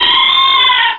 Cri de Delcatty dans Pokémon Rubis et Saphir.